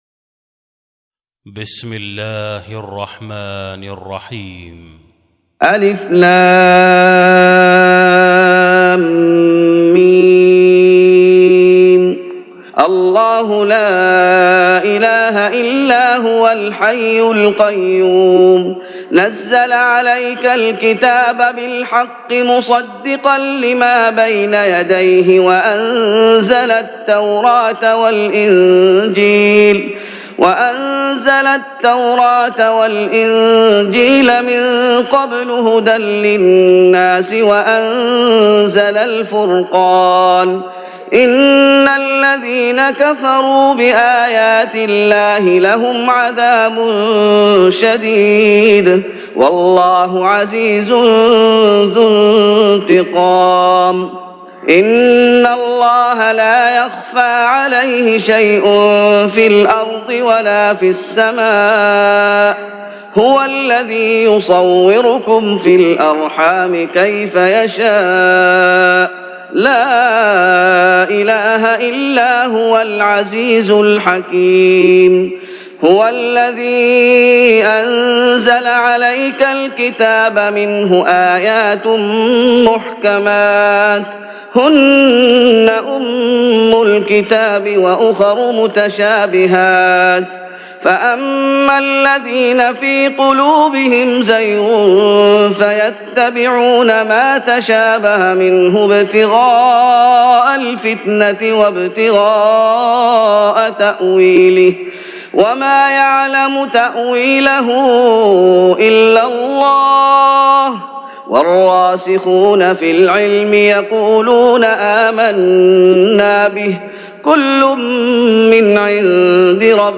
تهجد رمضان 1416هـ من سورة آل عمران (1-51) Tahajjud Ramadan 1416H from Surah Aal-i-Imraan > تراويح الشيخ محمد أيوب بالنبوي 1416 🕌 > التراويح - تلاوات الحرمين